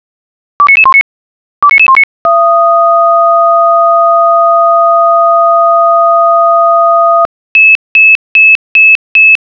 Alarmierung
Piepser Signal.wav